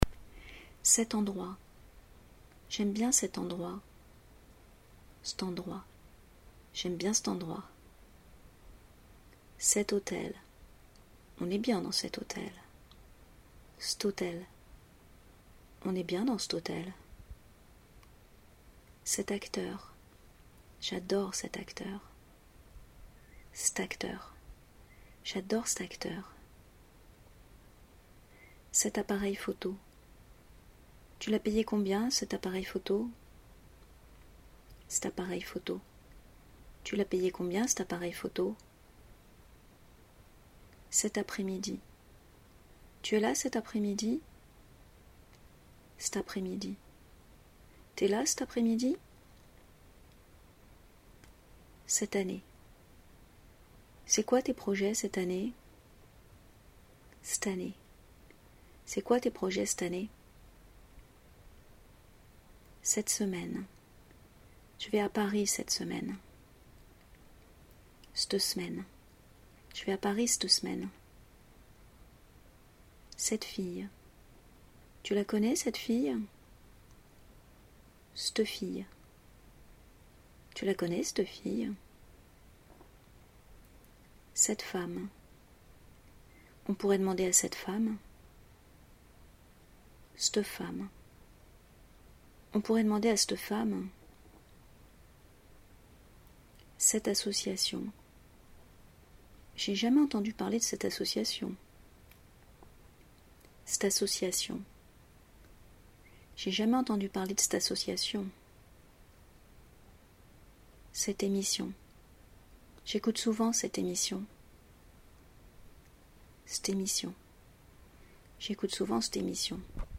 Je vous ai enregistré les deux façons de prononcer cet / cette :
la-prononciation-de-cet-ou-cette.mp3